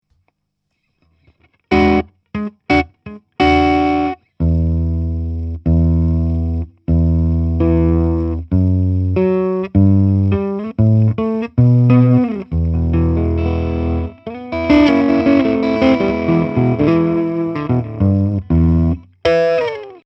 Laney Cub12 knackt und rauscht
Heute morgen mach ich den Verstärker an und habe immer dieses Knacken rauschen oder wie auch immer dabei, wie auf der Aufnahme zu hören ist.
Klingt das nach 1 kaputten Speaker?